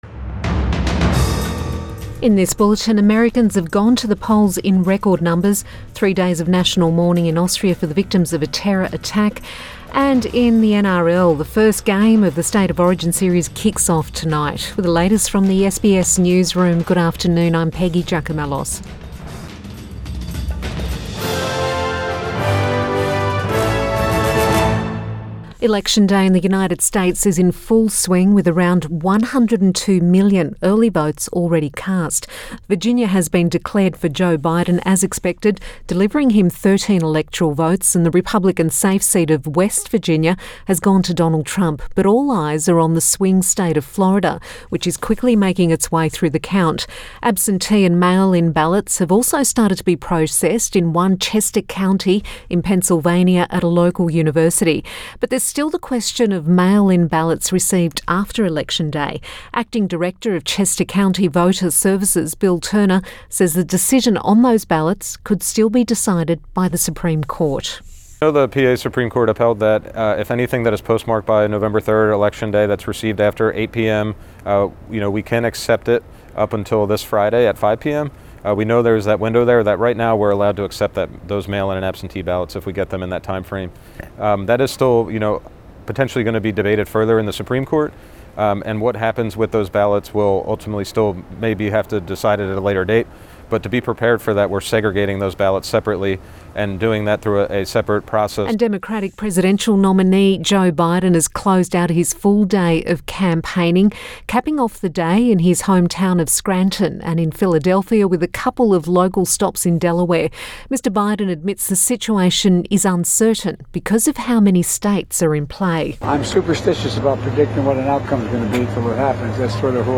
Midday bulletin 4 November 2020